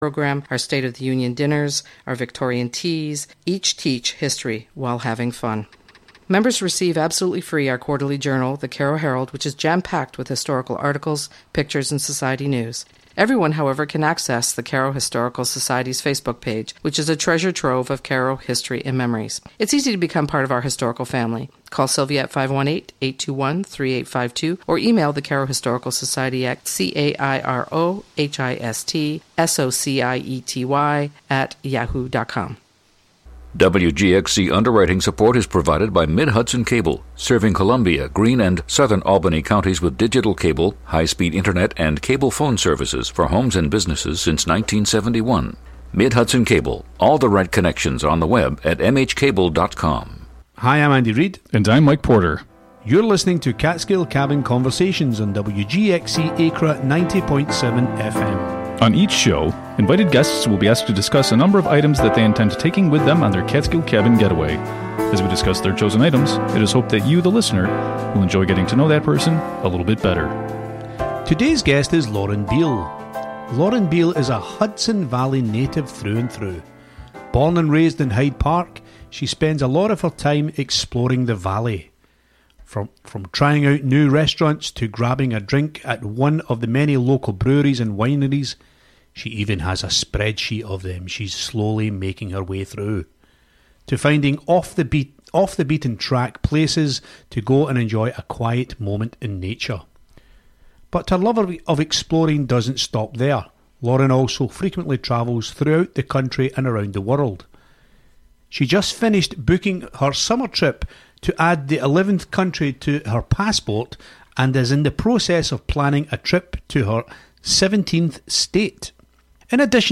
On each show, invited guests are asked to discuss a number of items that they would take with them to their Catskill Cabin get-away.